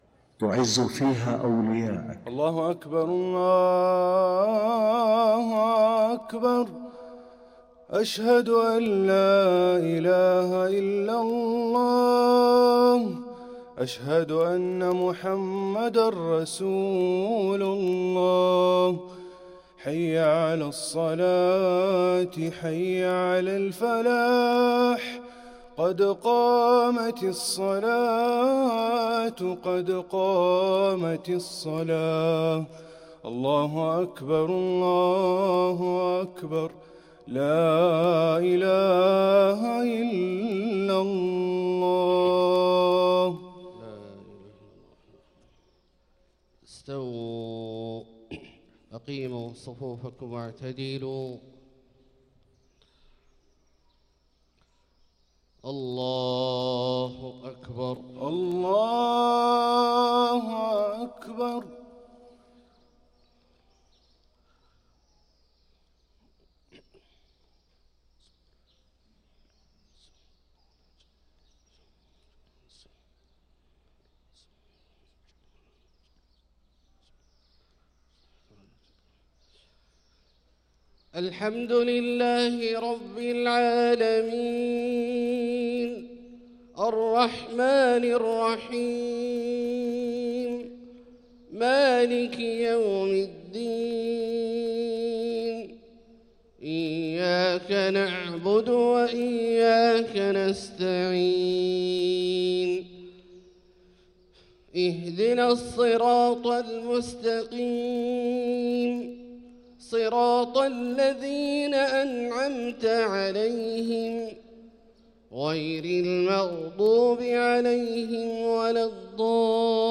صلاة الفجر للقارئ عبدالله البعيجان 24 رجب 1445 هـ
تِلَاوَات الْحَرَمَيْن .